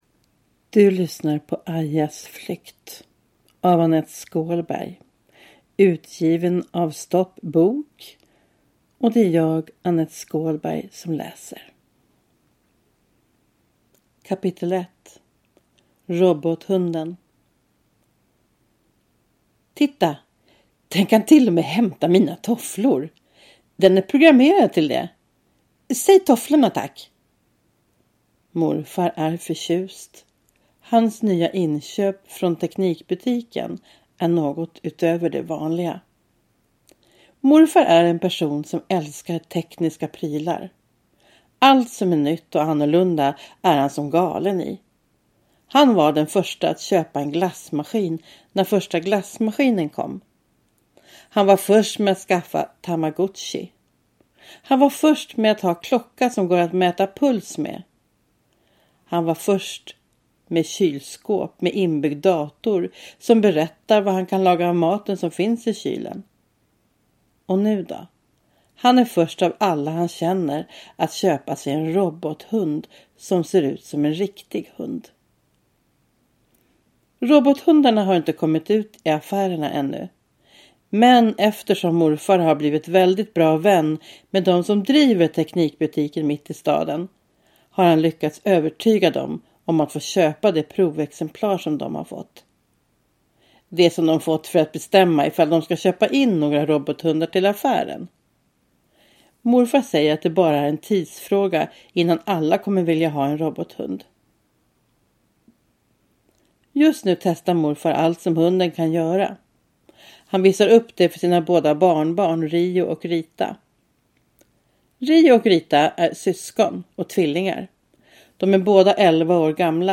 Aias flykt – Ljudbok